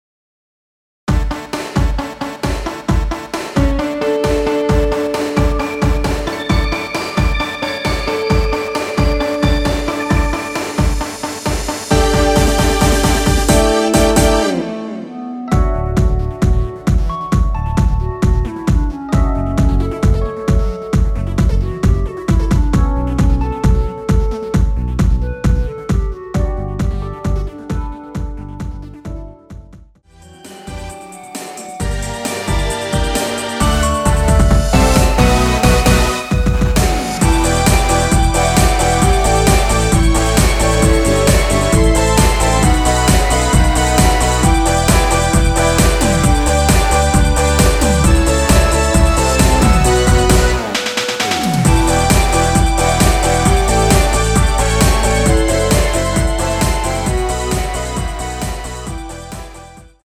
원키 멜로디 포함된 MR입니다.
멜로디 MR이라고 합니다.
앞부분30초, 뒷부분30초씩 편집해서 올려 드리고 있습니다.